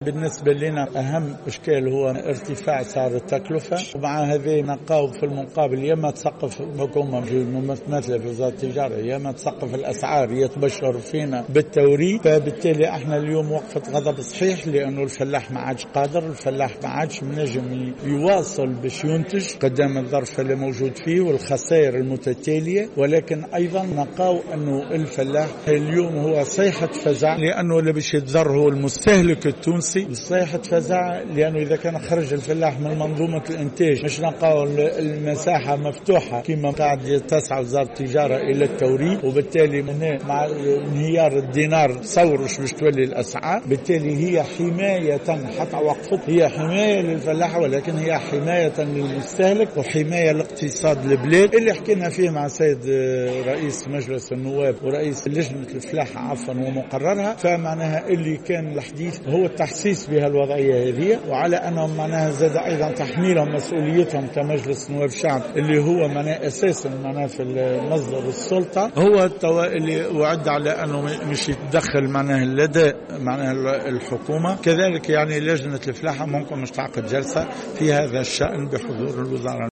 تصريح لمراسلة "الجوهرة اف أم"